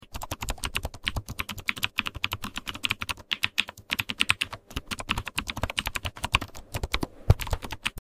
Keyboard Asmr Sounds for you sound effects free download